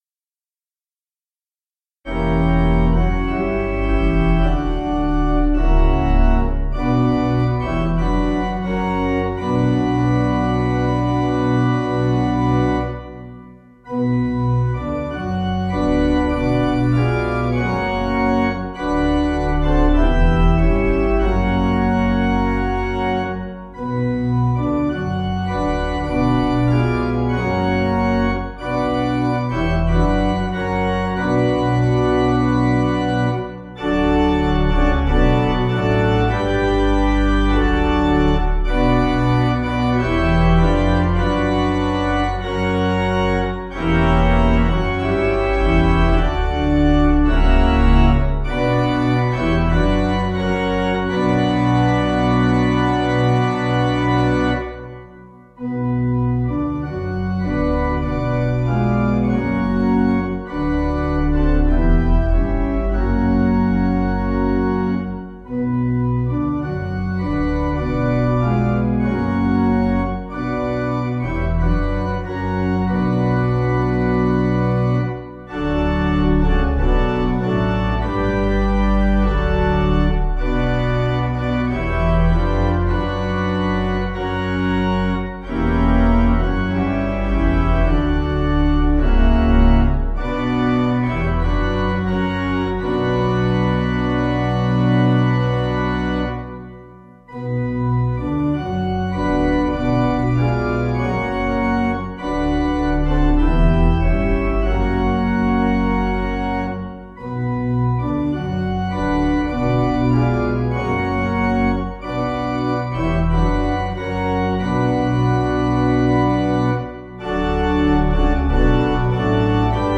Organ
(CM)   4/Bm 499.8kb